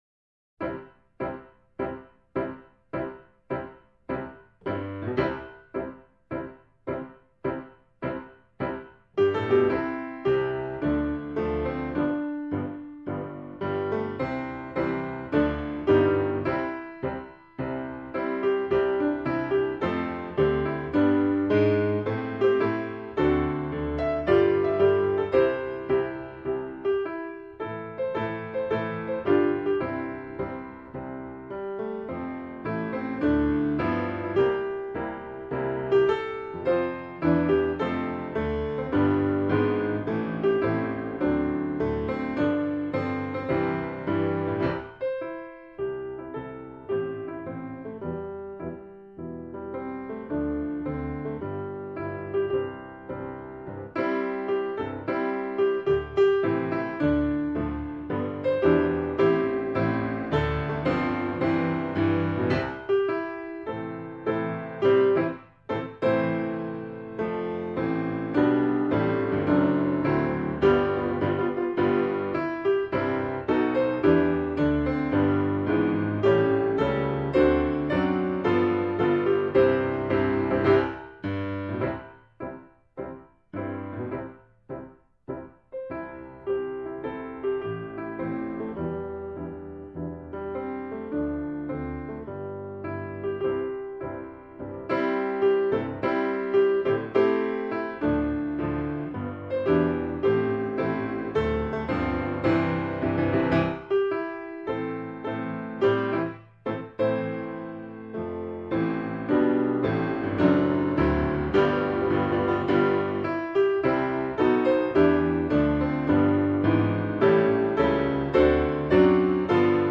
เปียโน